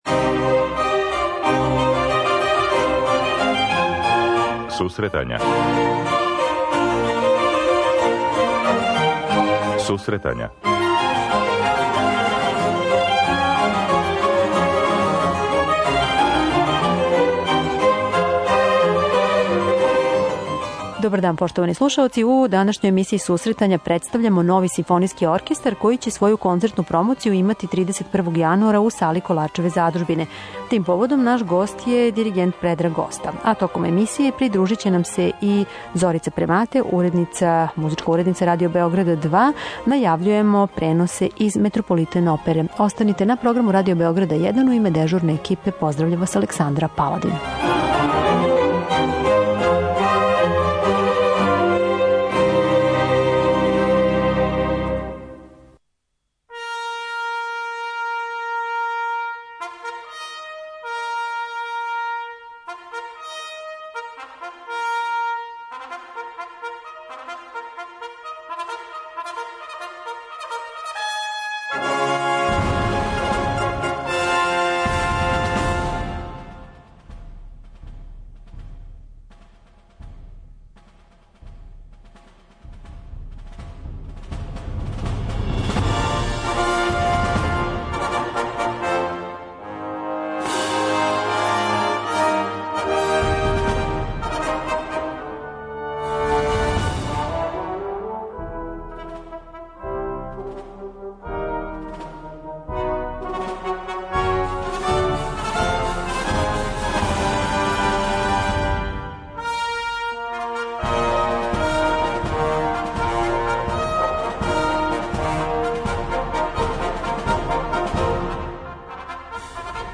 Гости емисије су диригент